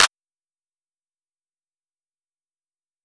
Clap (Since Way Back).wav